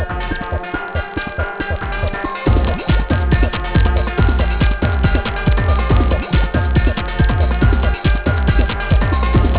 steel drums